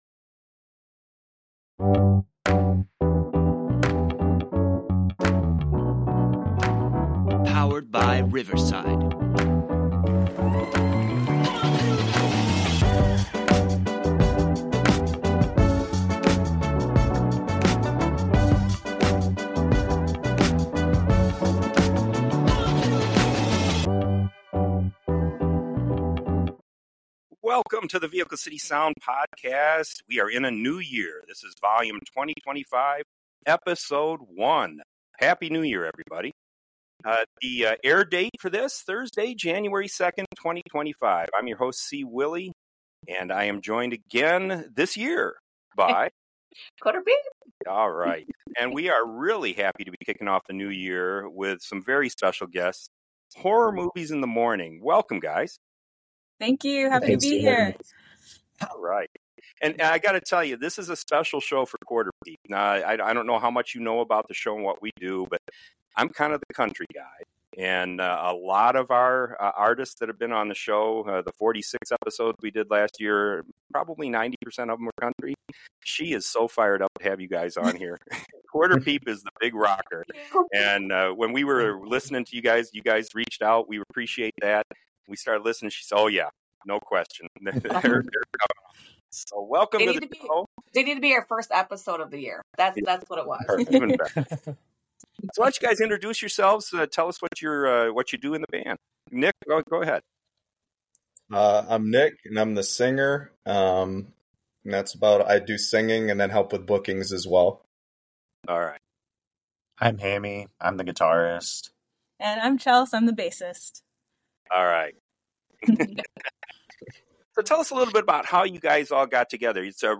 Their brand of alternative metal is distinct